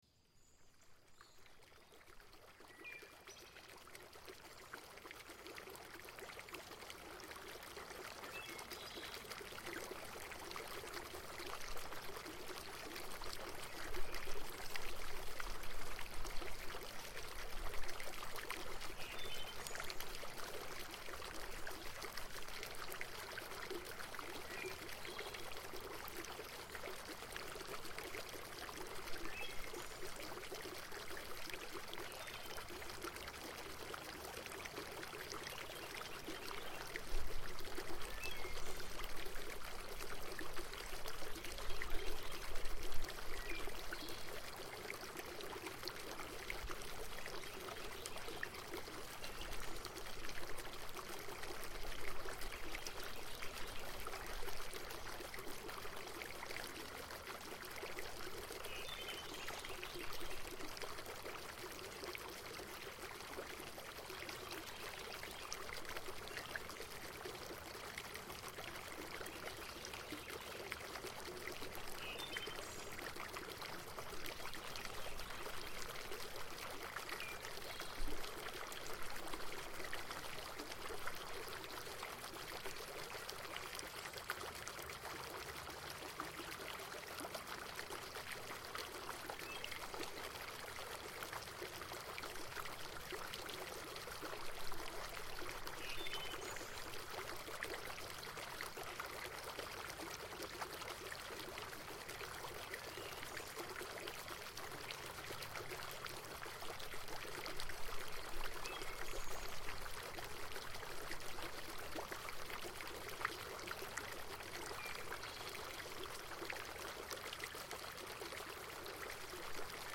Calm Prairie Ambience for Mindfulness for Emotional Relief – Relaxing Mind Journey with Subtle Rainfall
Each episode of Send Me to Sleep features soothing soundscapes and calming melodies, expertly crafted to melt away the day's tension and invite a peaceful night's rest.